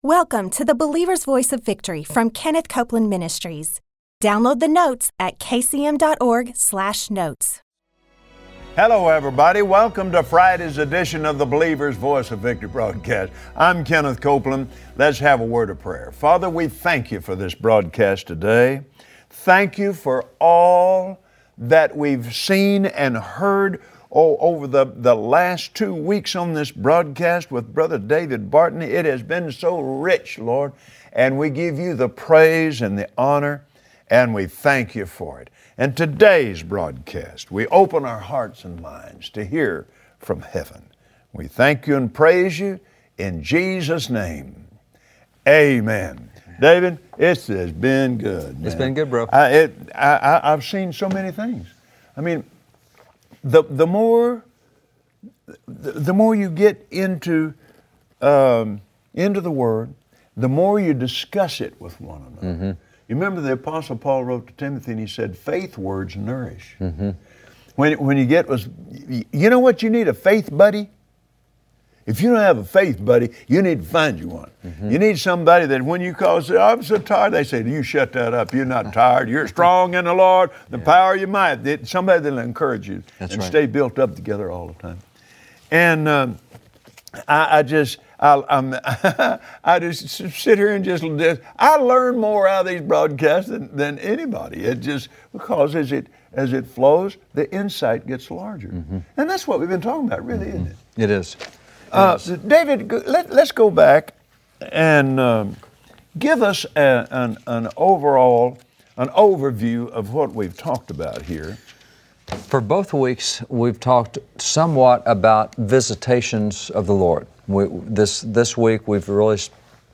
Believers Voice of Victory Audio Podcast for Friday 07/10/2015 Teaching new believers how to grow in God’s Word make the Church strong. Today on the Believer’s Voice of Victory, Kenneth Copeland and David Barton, teach us the importance of discipleship, and how Jesus, in your life, impacts those around you.